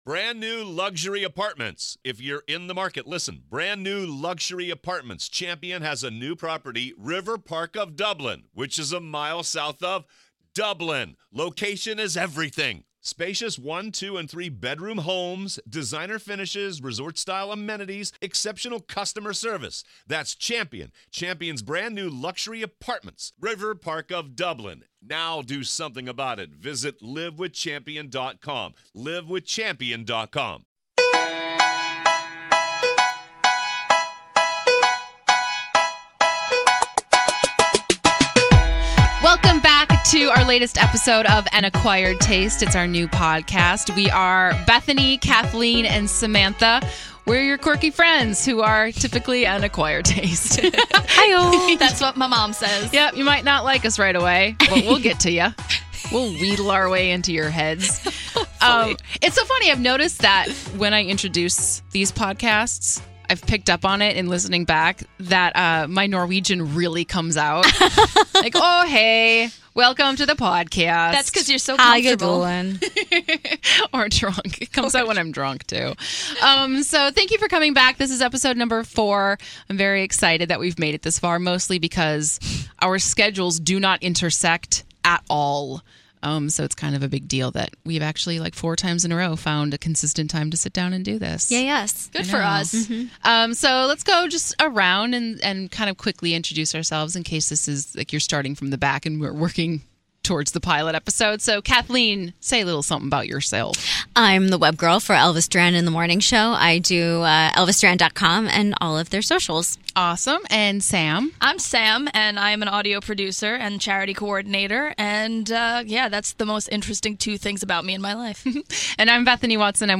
The ladies share their recent Google searches